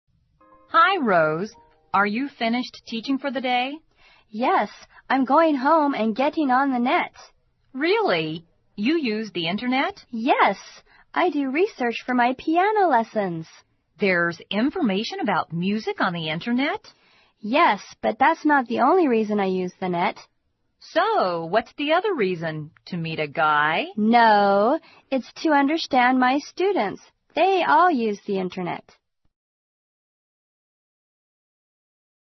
网络社交口语对话第4集：我要回家去上网了